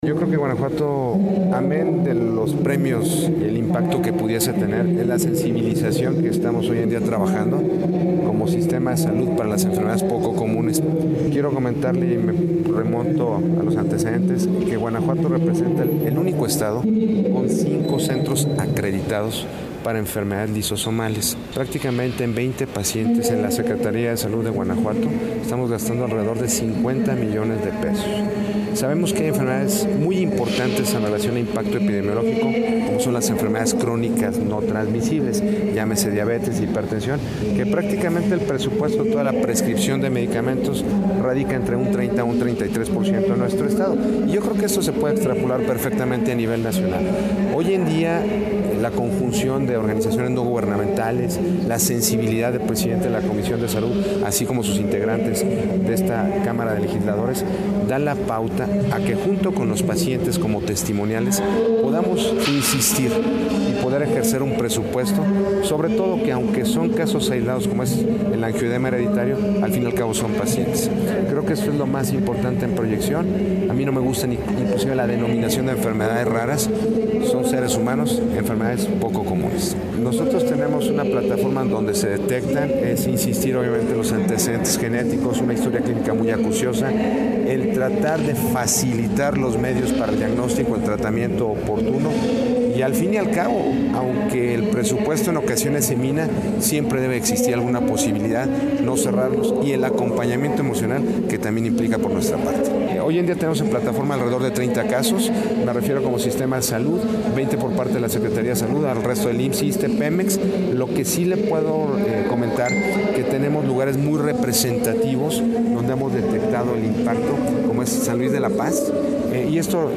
ENTREVISTA CON EL SECRETARIO DE SALUD DE GUANAJUATO QUIEN DIJO QUE SU ESTADO ES EL #1 PARA EL TRATAMINTO DE ENFERMEDADES LISOSOMALES
Ya en entrevista el secretario de salud Francisco Ignacio Ortiz Aldana explicó que Guanajuato, es la entidad con mayor número de unidades acreditadas para el tratamiento de este tipo de pacientes.